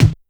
Kick_40.wav